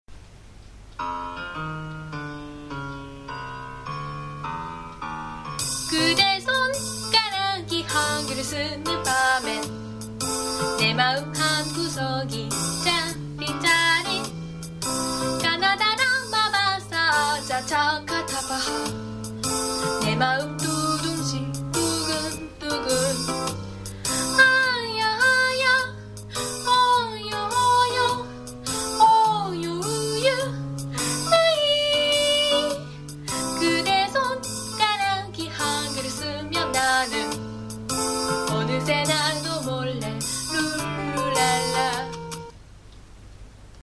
歌入りmp3はこちら